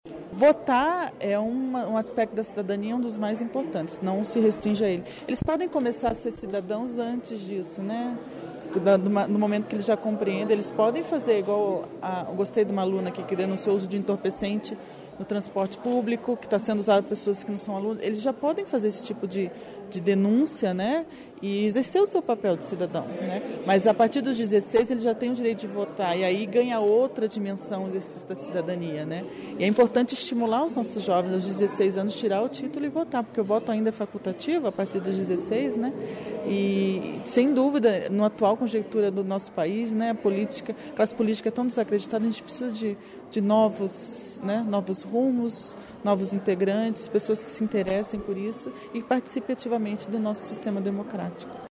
A magistrada Carolina Arantes da Conceição Nunes, responsável pela Vara da Infância, Juventude e Família de Campo Largo, destacou a importância do voto como uma das formas de exercício da cidadania e do comprometimento dos jovens com a sociedade. Confira a entrevista.